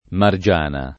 [ mar J# na ]